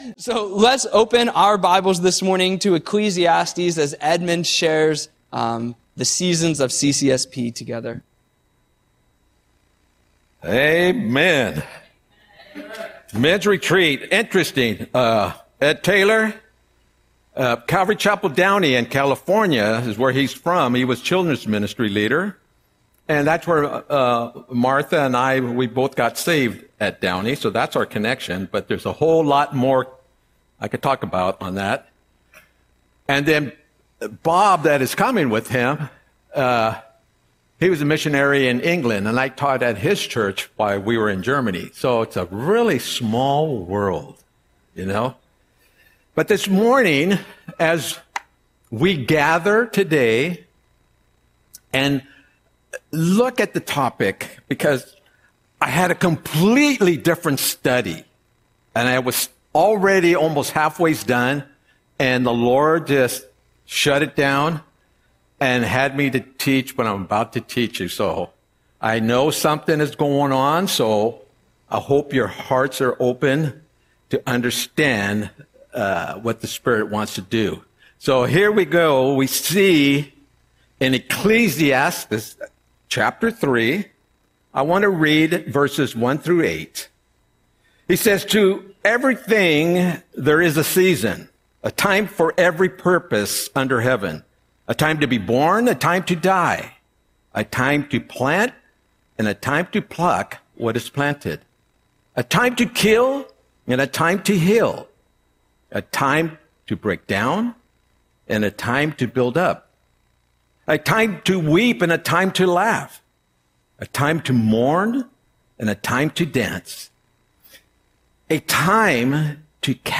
Audio Sermon - August 10, 2025